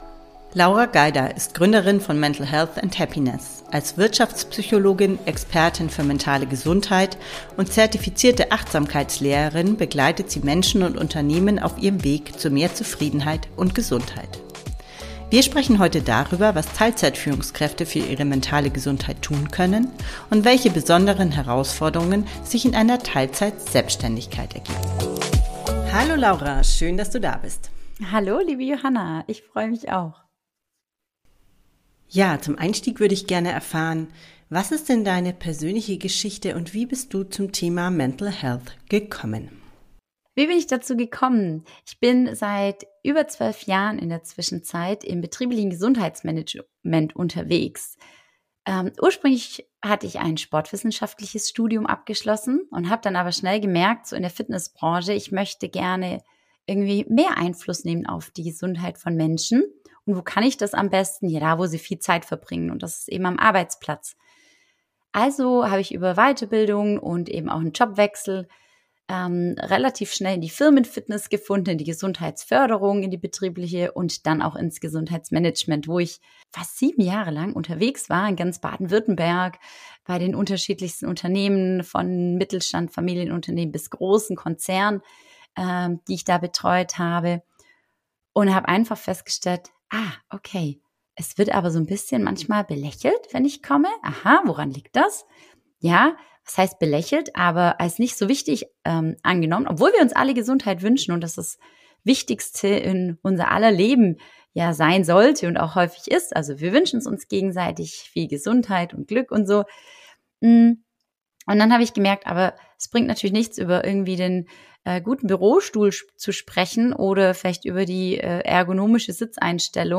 Kurzmeditation